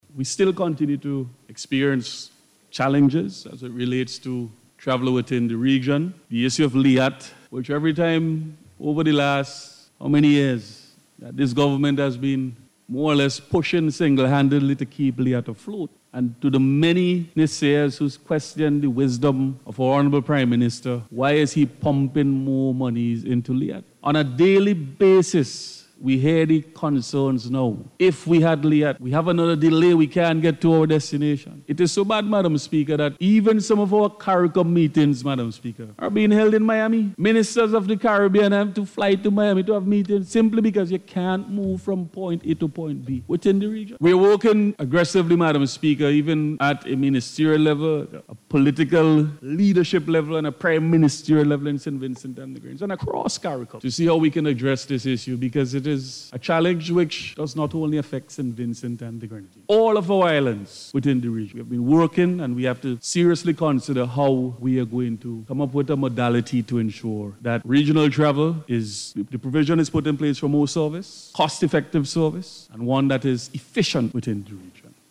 This was announced by Minister of Tourism, Civil Aviation, Sustainable Development and Culture Carlos James in parliament on Wednesday, as he contributed to the 2023 budget debate.